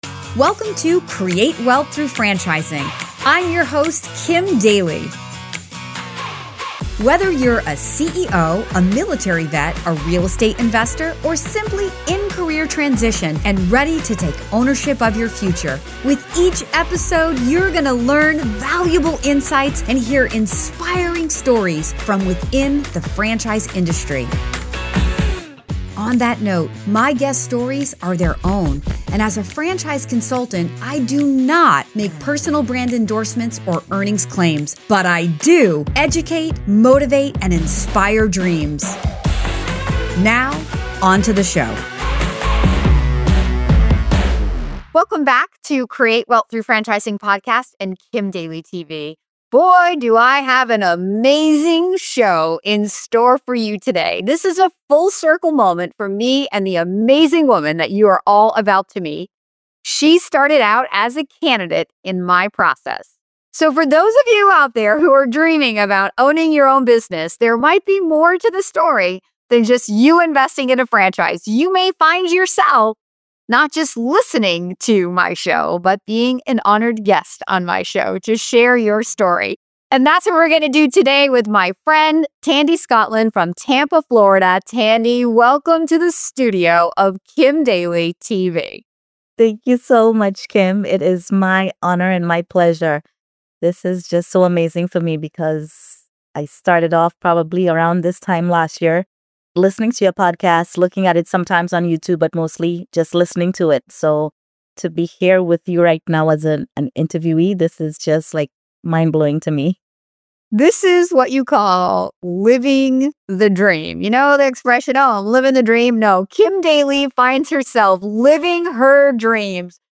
From navigating the franchise discovery process to embracing the mindset shift of business ownership, this conversation offers real-world insights into how franchising can reshape your personal and professional future.